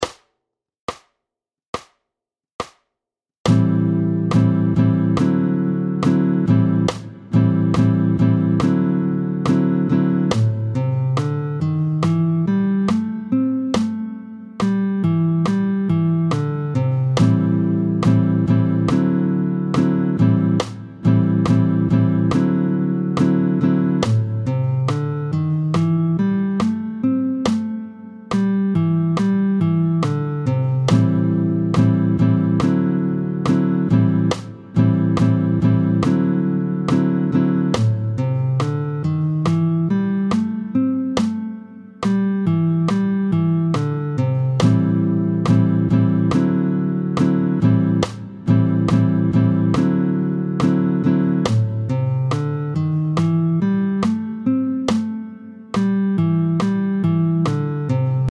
tempo 70